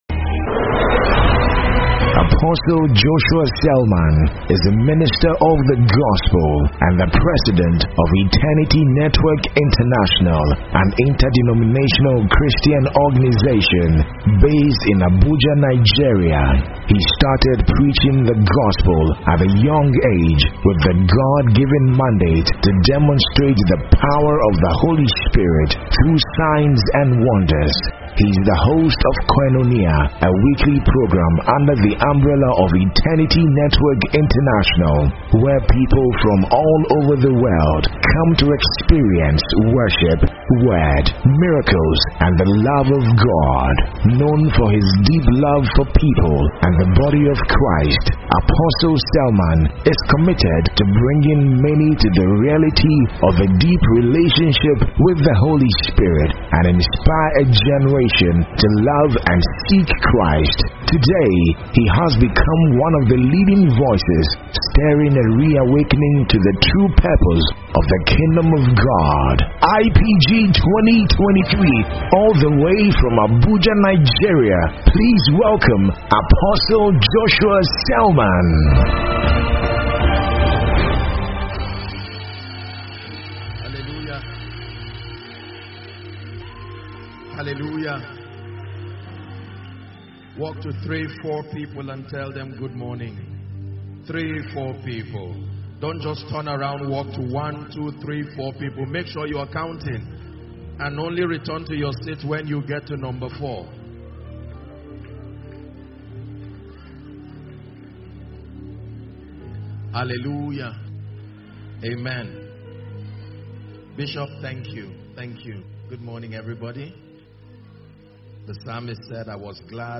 Download Gospel Messages/Sermons titled “EMPOWERED By LIGHT (1&2)” These are the kinds of sermons you will listen to that will transform your life greatly.